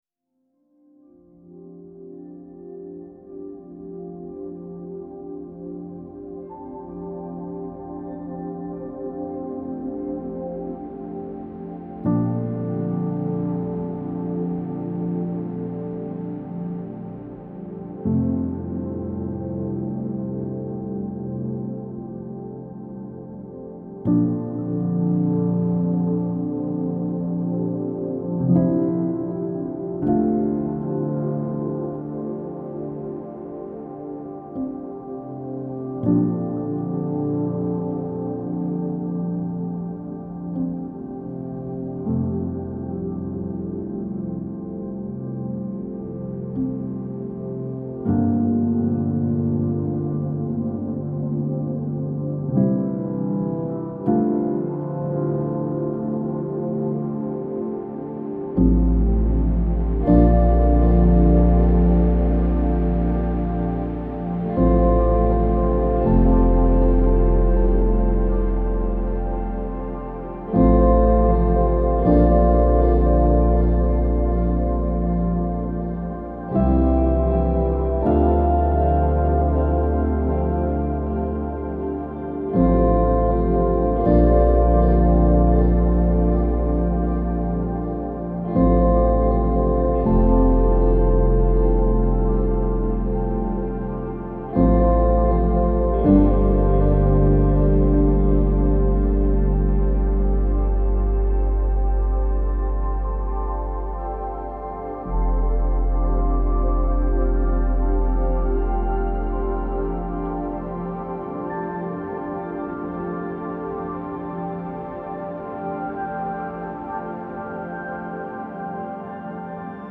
سبک امبینت , موسیقی بی کلام
موسیقی بی کلام عمیق